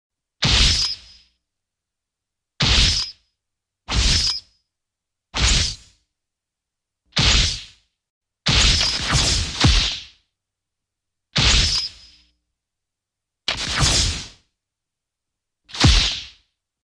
Tiếng Kiếm Chém trong phim Võ thuật Hồng Kông
Đánh nhau, vũ khí 394 lượt xem 04/03/2026
Hiệu ứng tiếng kiếm chém mang phong cách đặc trưng của các bộ phim võ thuật Hồng Kông kinh điển, với âm thanh sắc lẹm, vang dội và đầy uy lực. Đây là âm thanh mô phỏng những cú vung kiếm xé gió (whoosh) kết hợp với tiếng kim loại va chạm hoặc va chạm vào mục tiêu một cách dứt khoát.